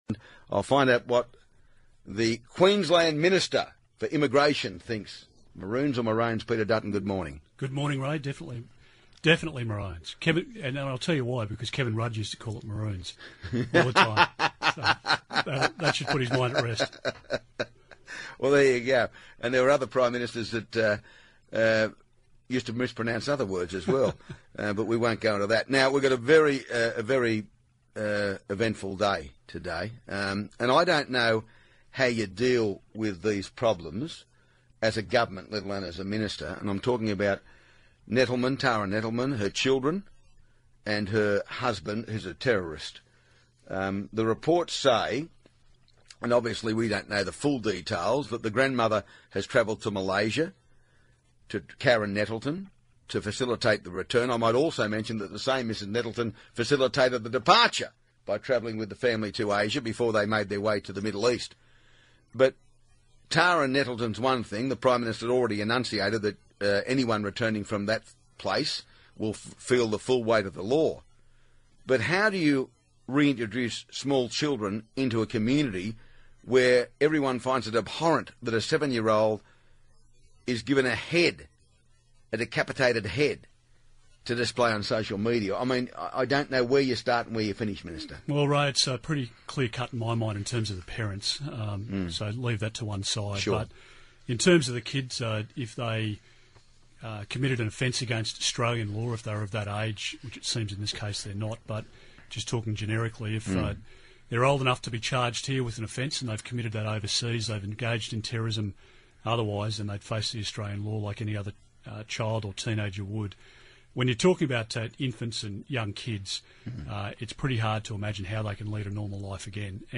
Immigration Minister Peter Dutton joins Ray in the studio to discuss Australians wanting to return from the Islamic State, problems inside detention centres, and illegal workers in Australia